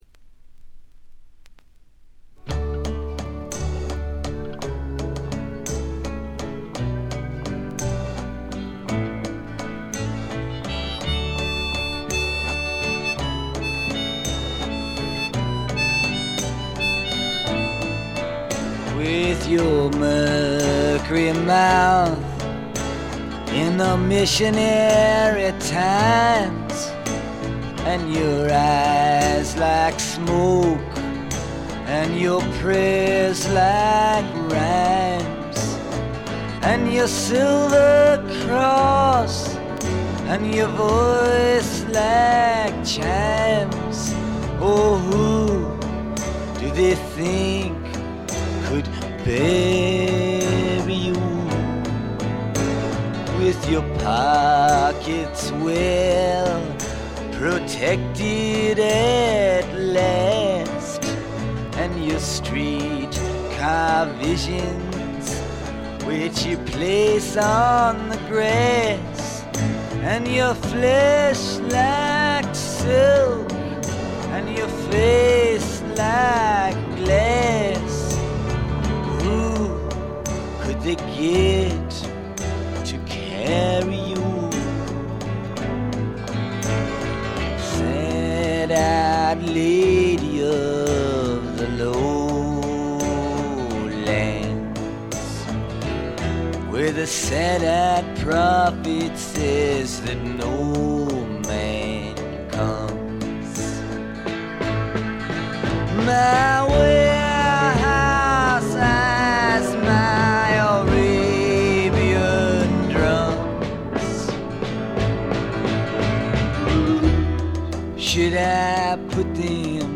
試聴曲は現品からの取り込み音源です。
vocals, guitar, harmonica, piano